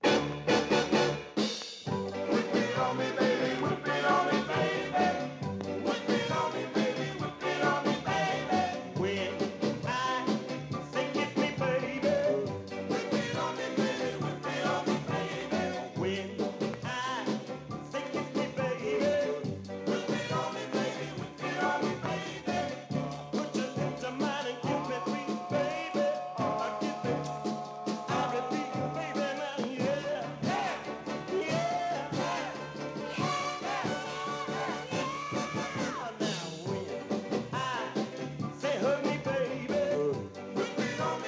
Mod & R&B & Jazz & Garage